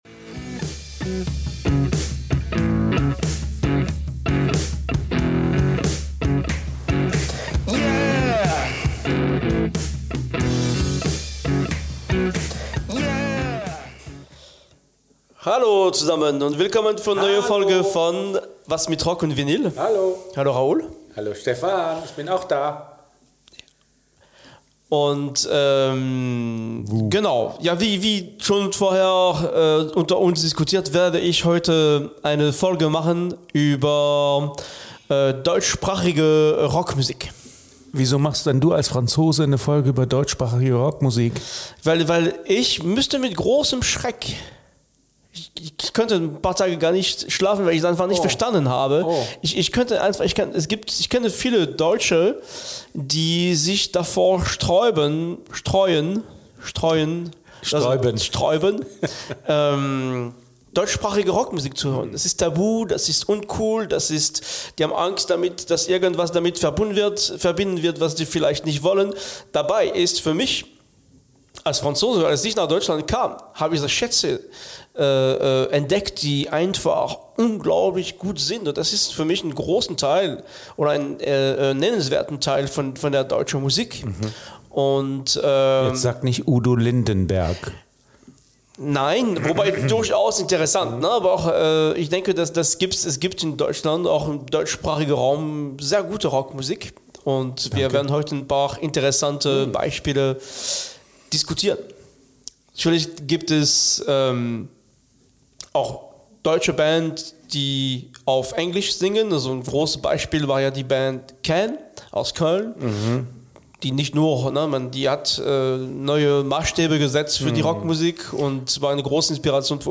Juli 2022 Nächste Episode download Beschreibung Teilen Abonnieren Ein Franzose und ein Deutscher unterhalten sich über deutschsprachige Rockmusik.